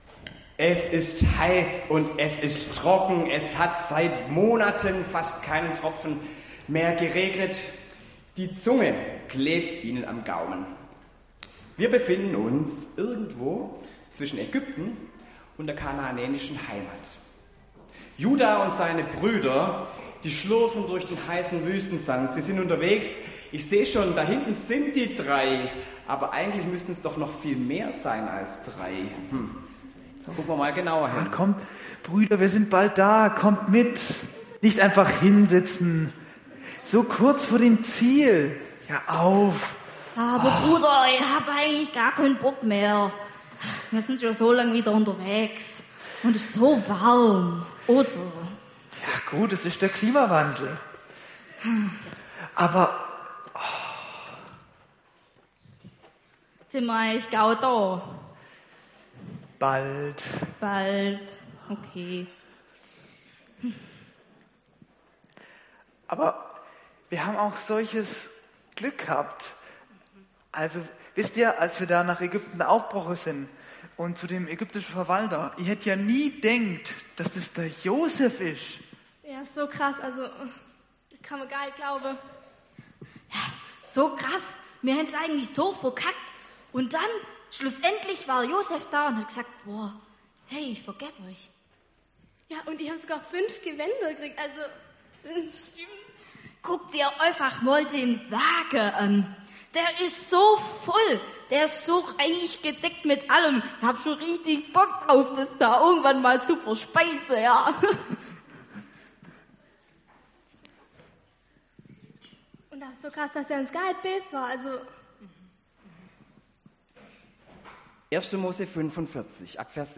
Predigt
mit gespielten Szenen im Gottesdienst am Gemeindefest
Theaterteam